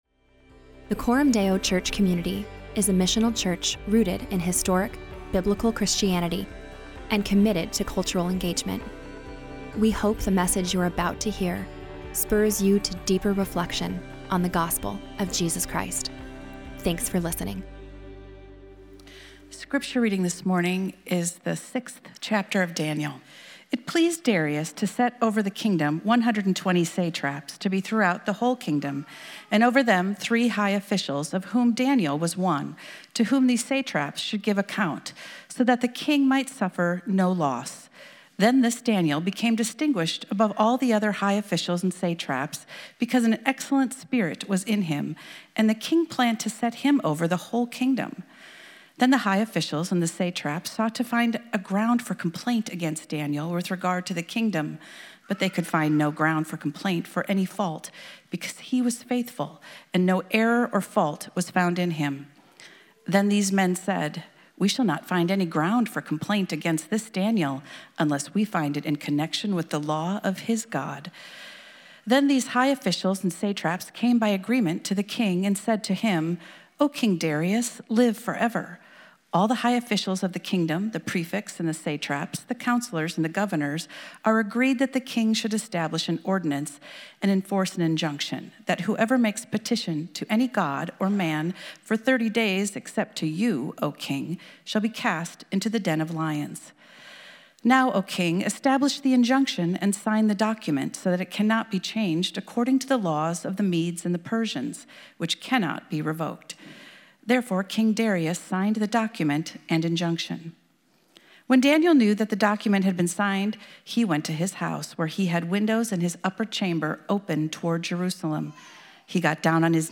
God Is More Merciful Than You Think | Exodus 34:1-10 Coram Deo Church Sermon Audio podcast